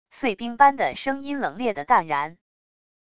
碎冰般的声音冷冽的淡然wav音频